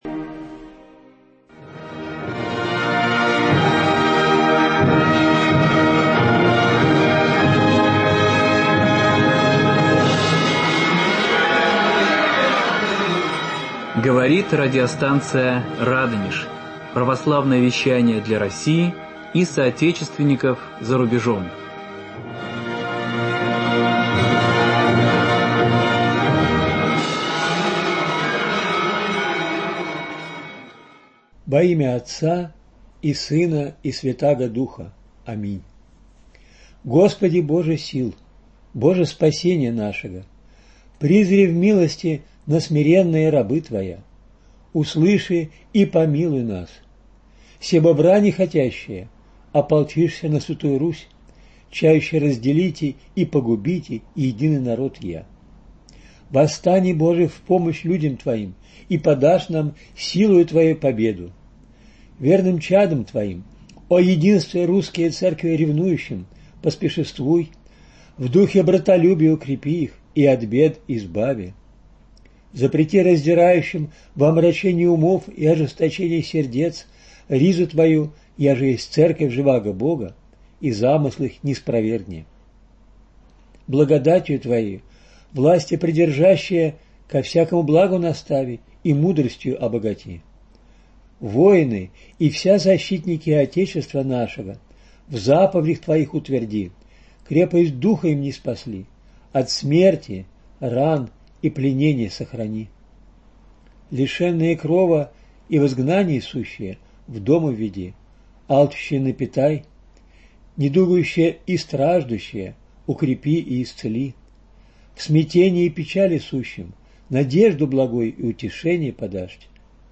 Буди благословенна благодать всечестнаго Поста: беседа